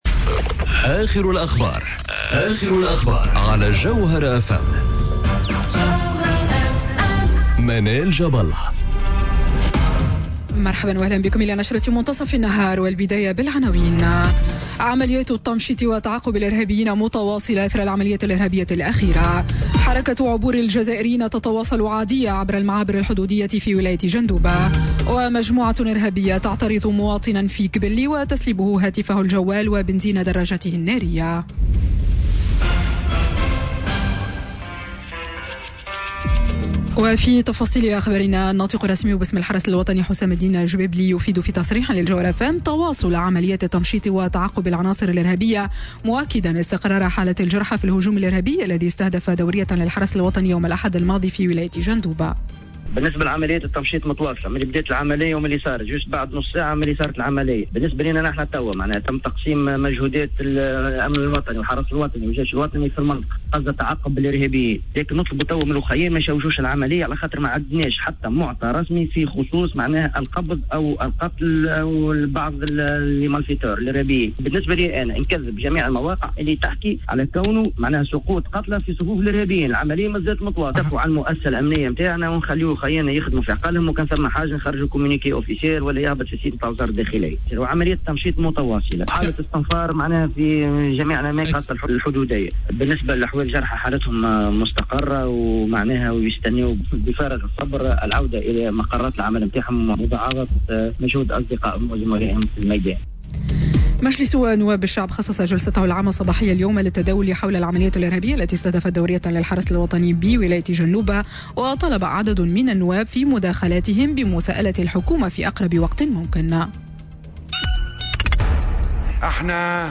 نشرة أخبار منتصف النهار ليوم الثلاثاء 10 جويلية 2018